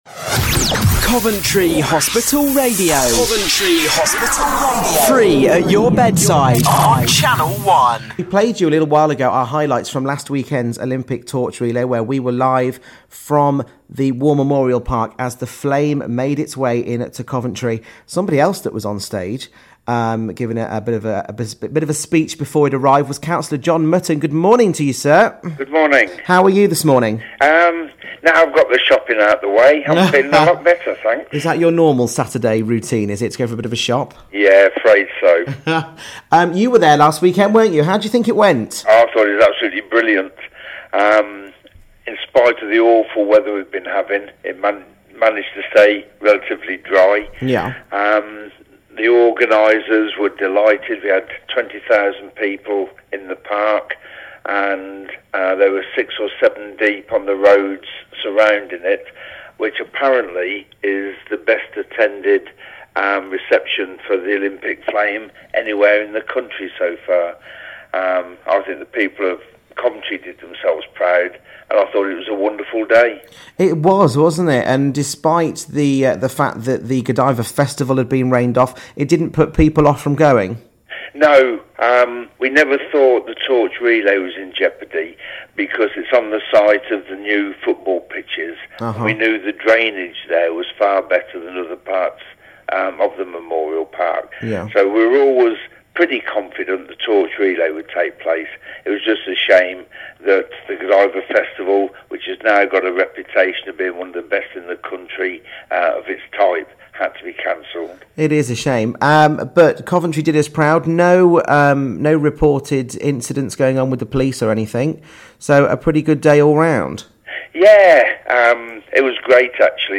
Chat on Coventry Hospital Radio with Cllr Mutton about how he felt the Olympics would be of benefit to our part of the world... Oh and also how he likes a bit of shopping on a Saturday morning!